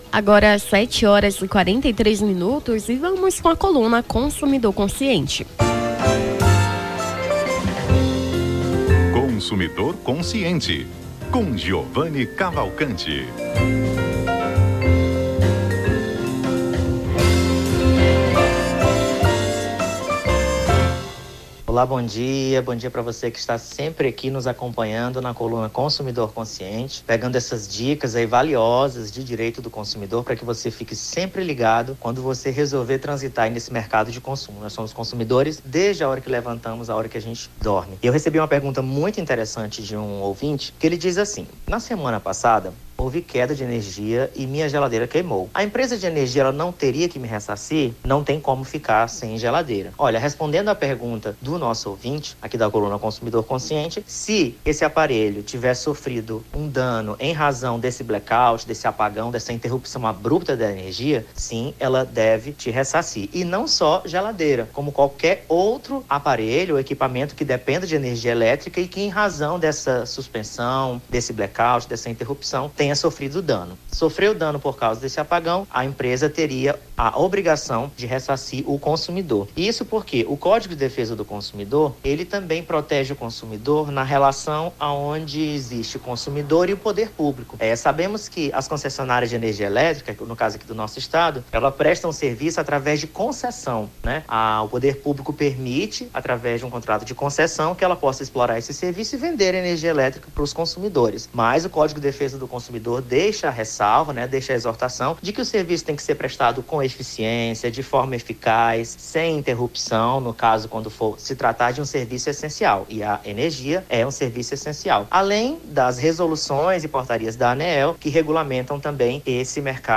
Consumidor Consciente: advogado tira-dúvidas sobre direito do consumidor